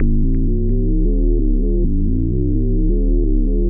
bass03.wav